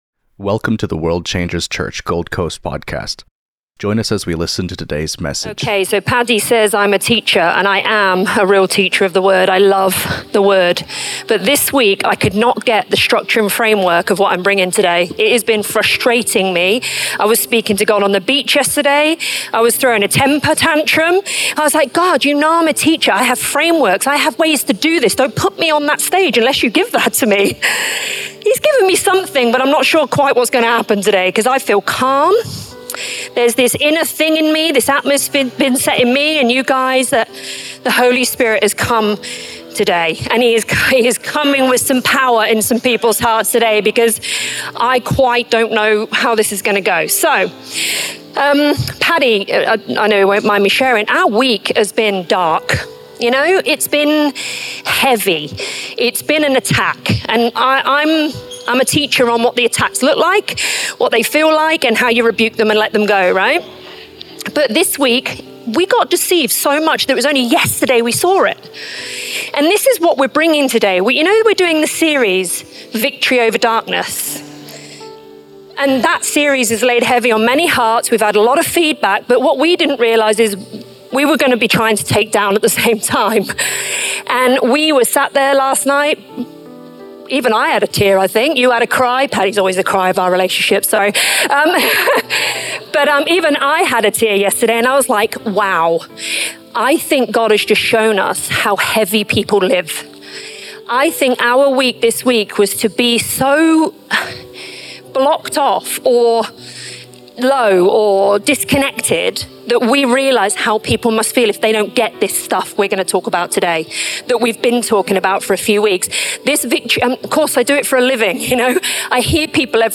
This message focuses on repositioning in Christ, shifting from darkness to victory through renewed identity and faith. It highlights the battle believers face, not in external circumstances, but in the mind and heart. Emphasizing the power of truth over deception, the sermon calls for confronting hidden struggles, exposing darkness, and embracing the renewing work of the Holy Spirit.